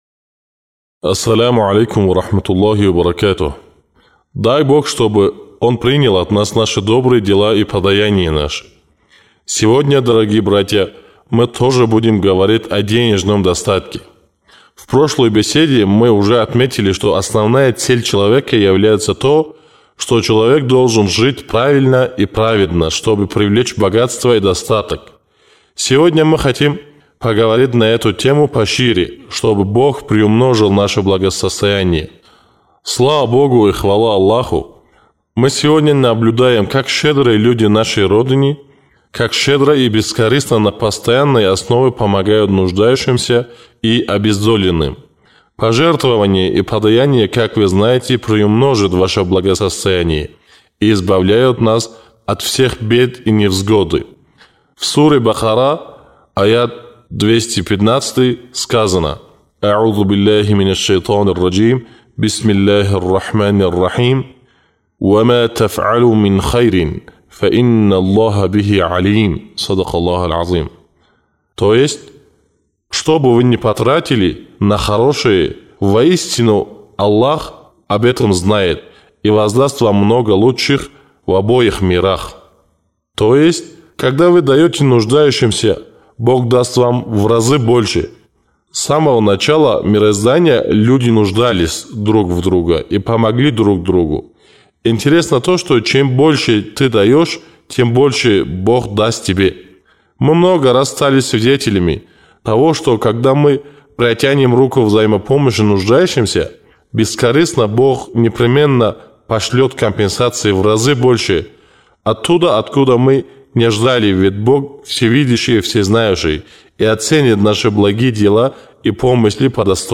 Как благотворительность влияет на нашу повседневную жизнь? Чтобы найти ответы на все эти вопросы, вы можете прослушать вторую речь «Благотворительность».